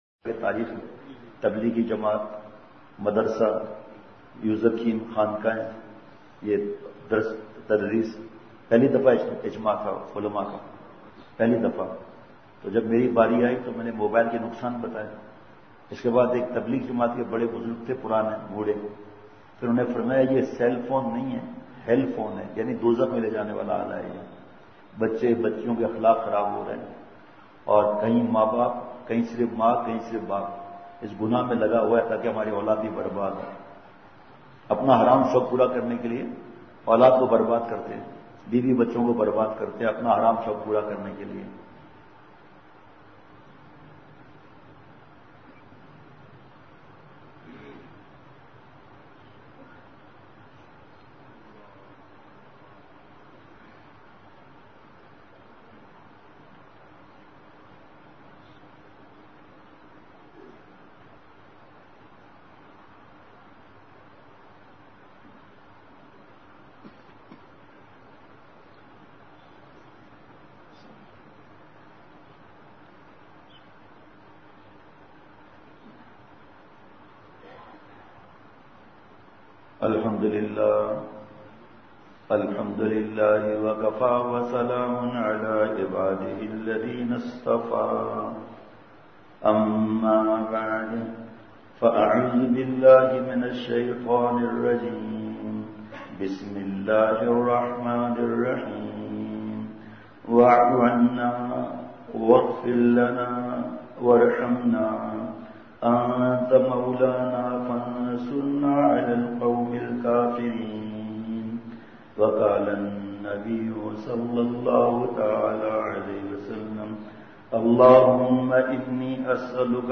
بمقام: مرکزی جامع ۔مسجد پشین